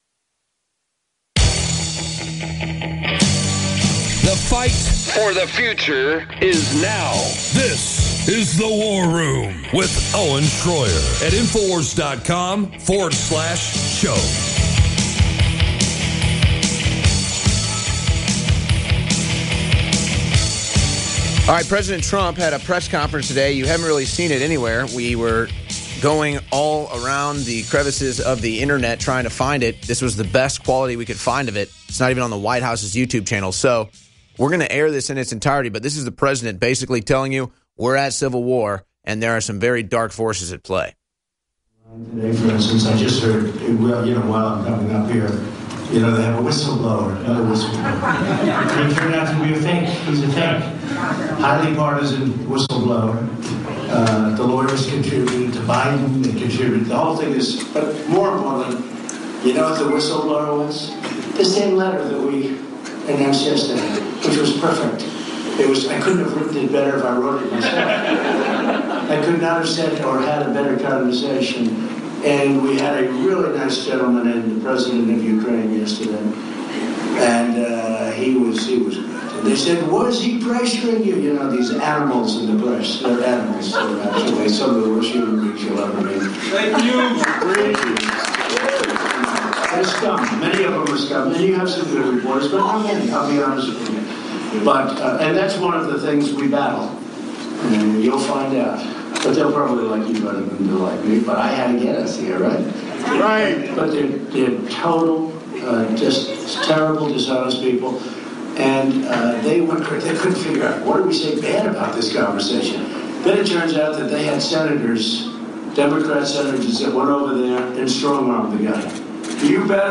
We hear from Veterans today on the Veterans call in special as they weigh in on the second coup attempt the Democrats are running in attempt to remove Trump from office.